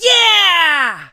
leon_start_vo_05.ogg